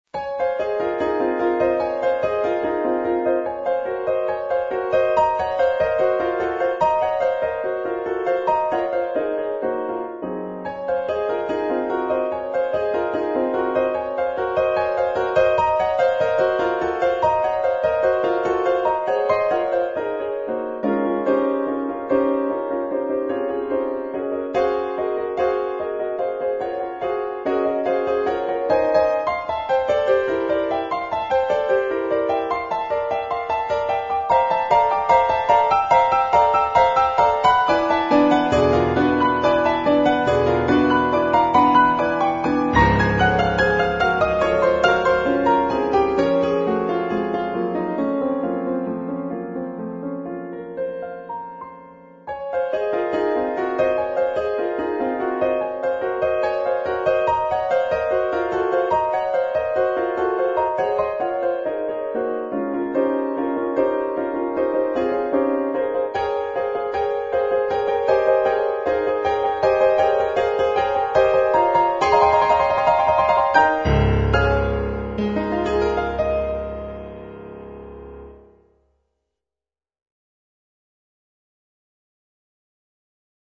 Instrument(s): piano solo